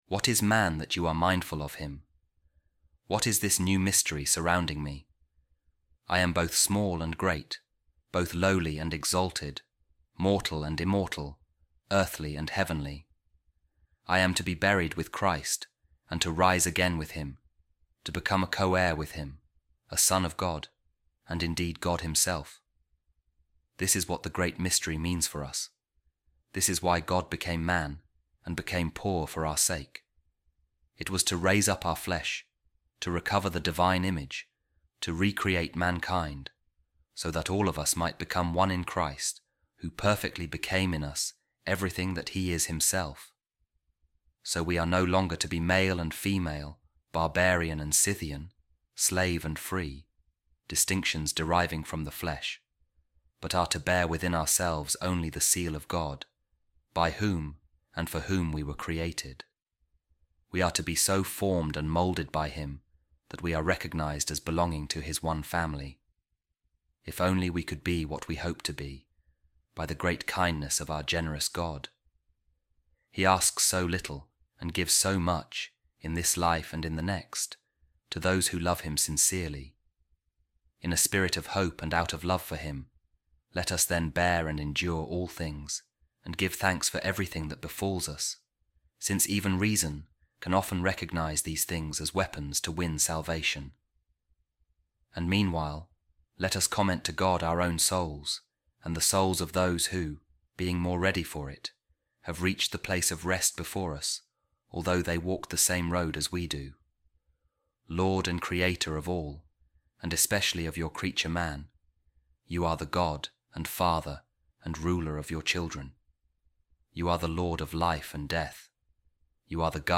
Office Of Readings | Week 31, Friday, Ordinary Time | A Reading From The Addresses Of Saint Gregory Nazianzen | It Is A Holy Thought To Pray For The Dead